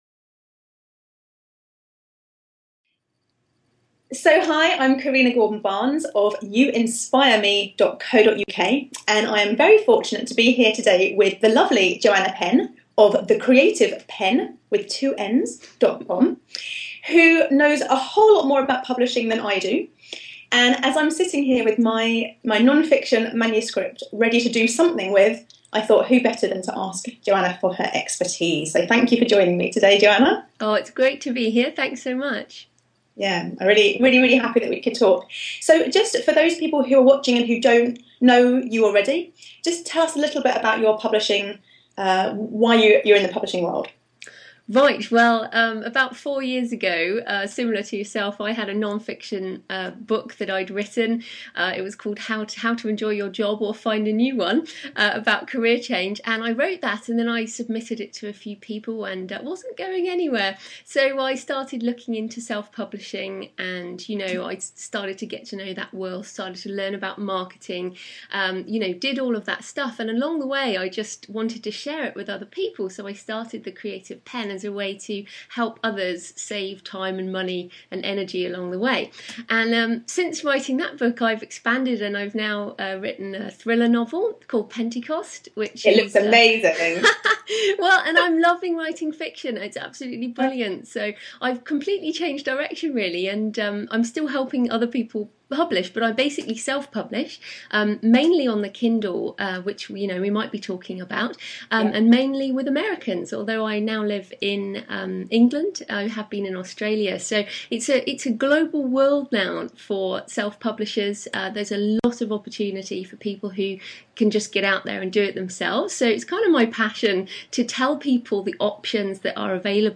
It's like a coaching session in public! We have a lot of fun laughs but there's also a lot of valuable information if you are just starting out in your publishing journey.